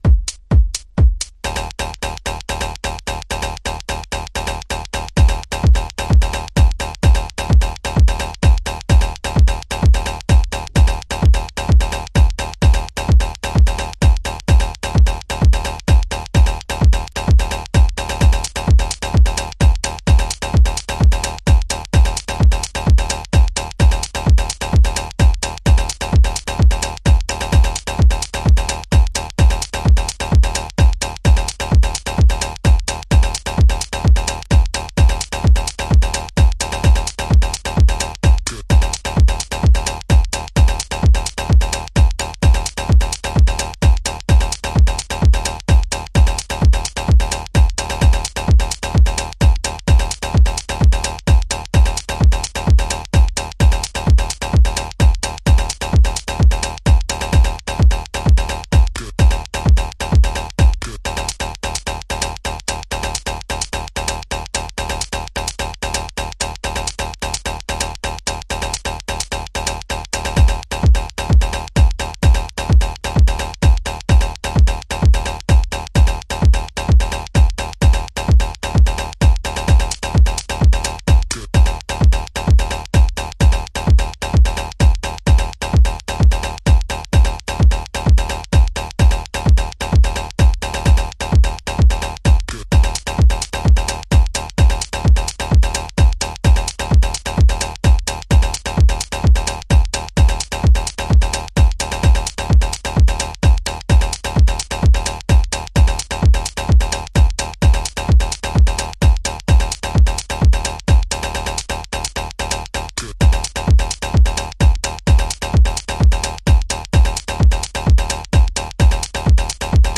House / Techno
チョップした痙攣グルーヴでラストまで疾走するRAW TECHNO